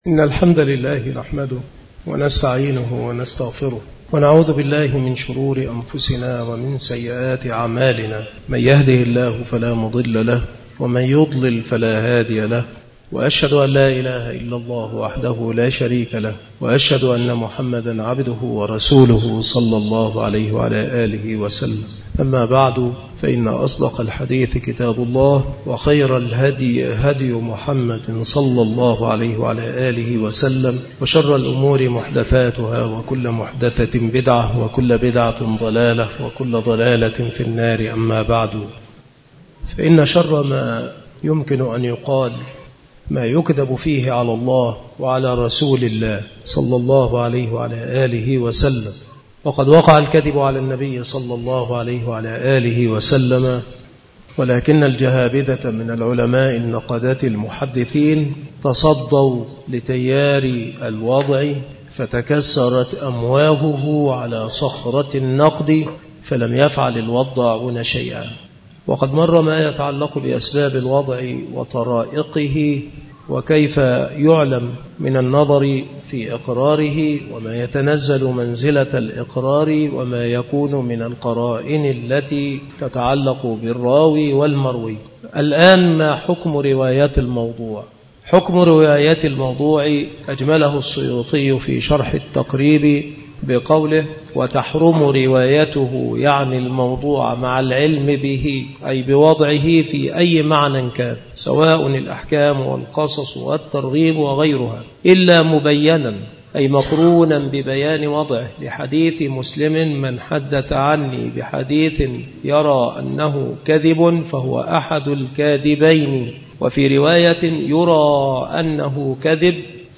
مكان إلقاء هذه المحاضرة بالمسجد الشرقي بسبك الأحد - أشمون - محافظة المنوفية - مصر عناصر المحاضرة : حكم رواية الموضوع. جهود العلماء في مواجهة الوضع. التثبت في السماع والتشديد فيه.